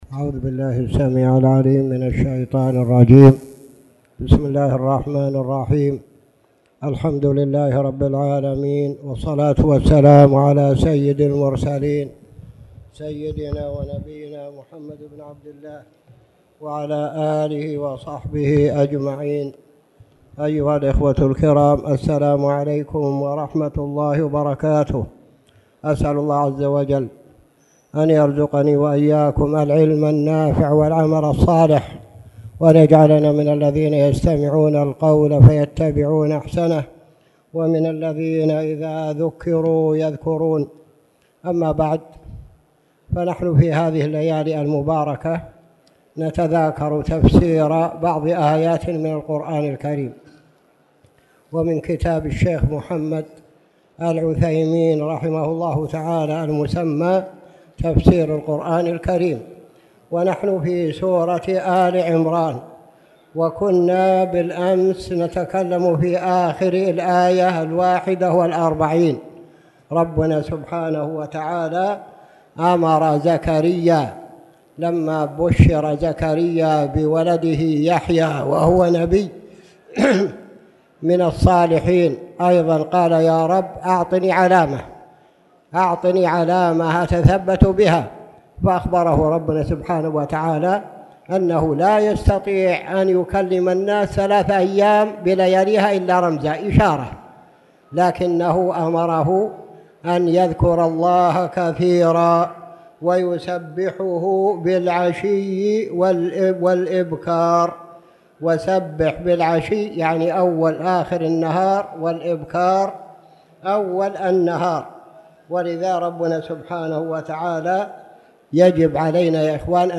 تاريخ النشر ١٨ جمادى الأولى ١٤٣٨ هـ المكان: المسجد الحرام الشيخ